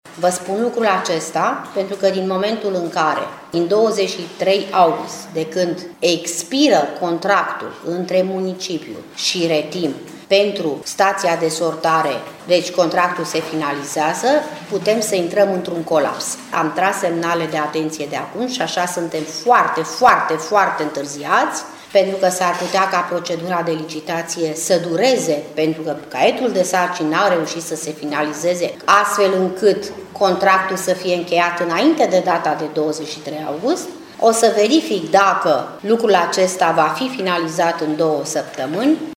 Ministrul Mediului, Graţiela Gavrilescu a declarat că a cerut municipalităţii să îşi asume responsabilitatea pentru construirea acestei staţii şi că are termen două săptămâni pentru a întocmi modul în care va face licitaţia pentru operarea staţiei de sortare de la Timişoara.